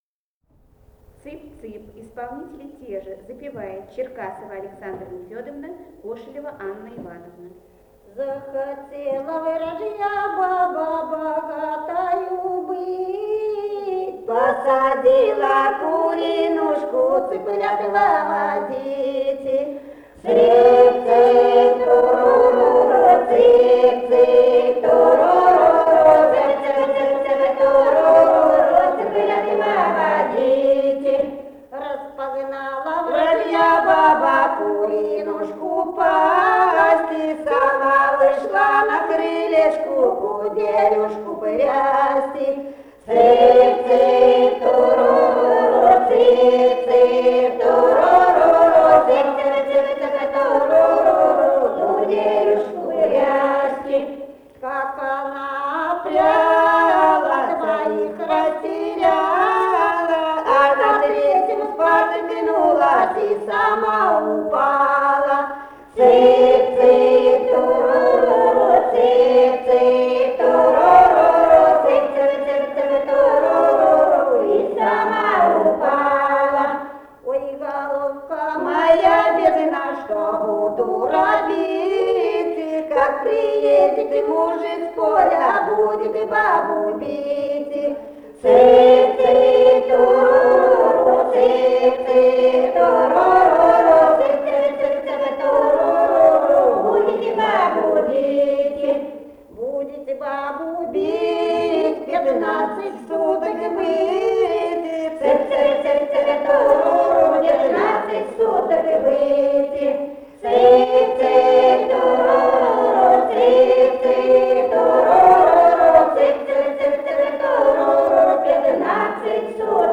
Этномузыкологические исследования и полевые материалы
«Захотела вражья баба» (шуточная украинская).
Самарская область, с. Печинено Богатовского района, 1972 г. И1318-27